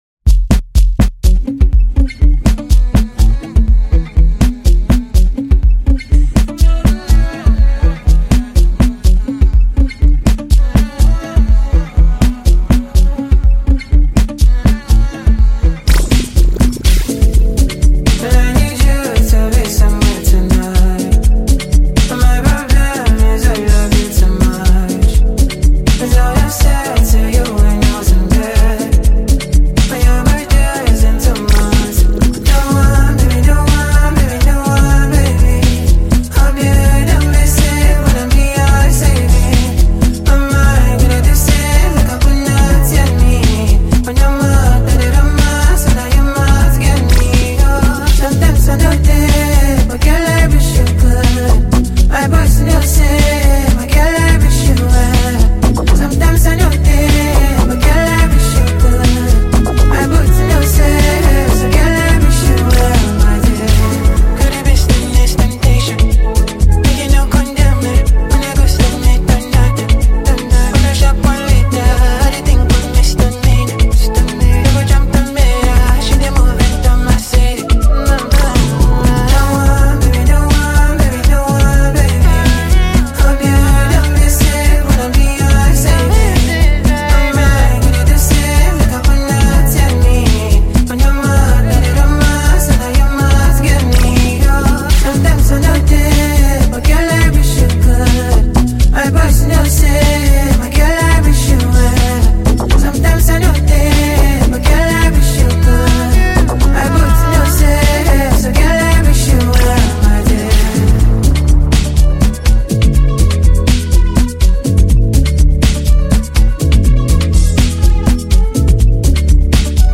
single
feel-good energy and irresistible rhythm